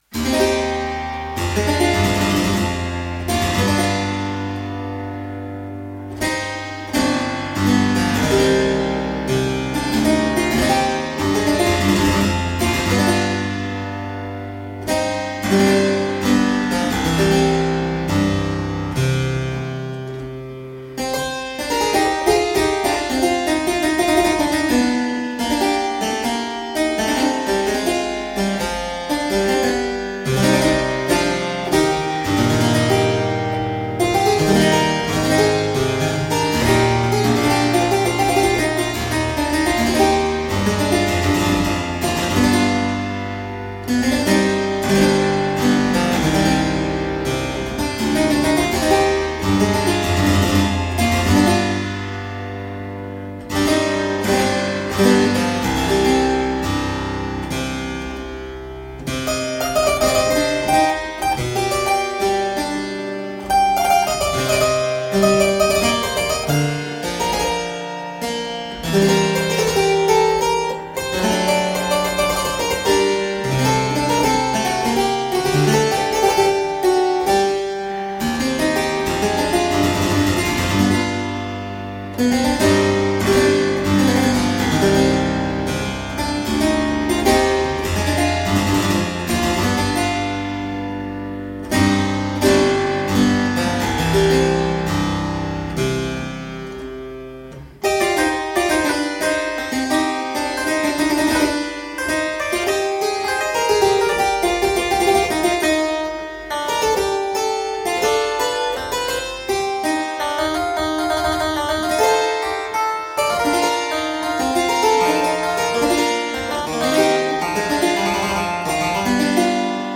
Solo harpsichord music.
played on a wonderful original French harpsichord of 1661